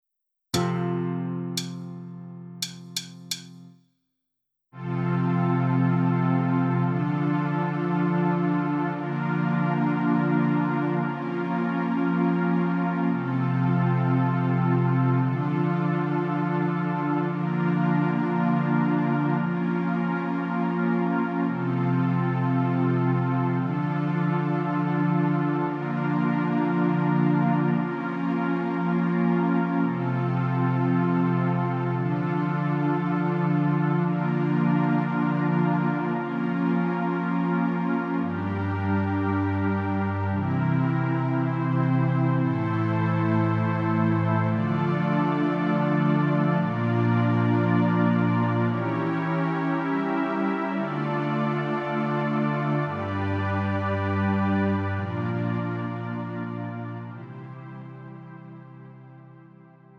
음정 원키 4:20
장르 가요 구분